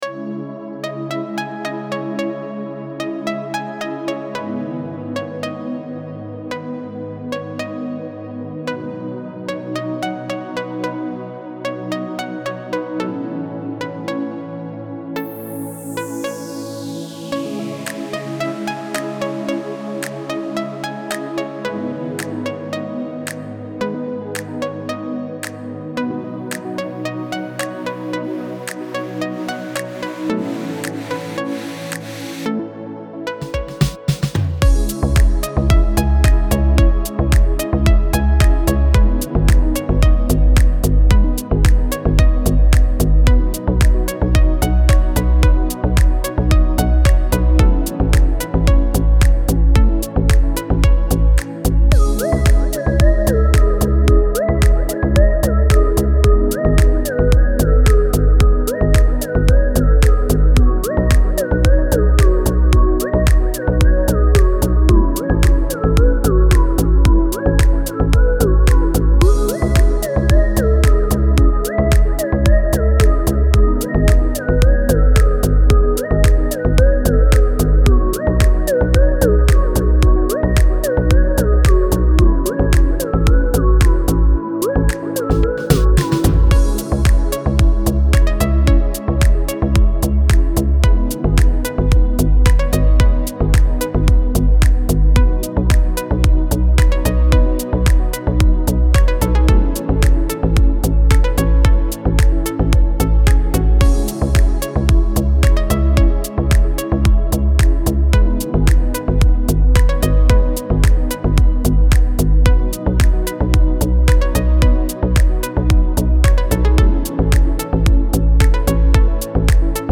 سبک دیپ هاوس , ریتمیک آرام , موسیقی بی کلام